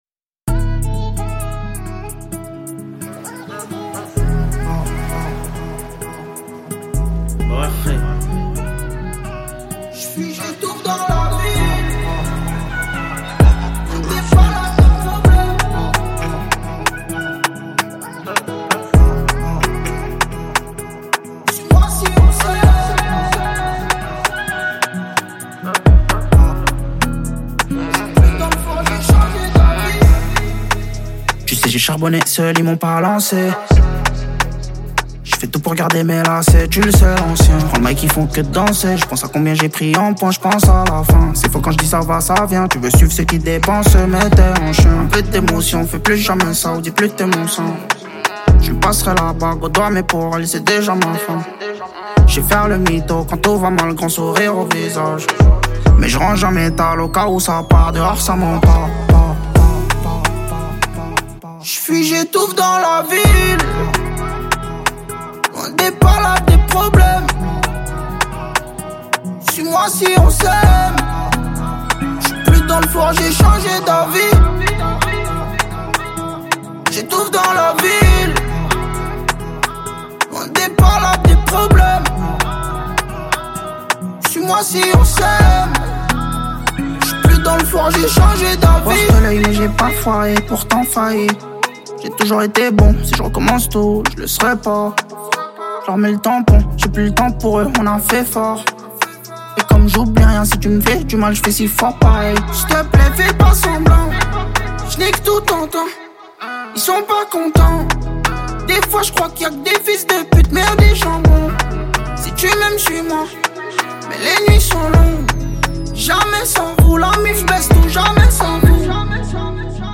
5/100 Genres : french rap, french r&b Télécharger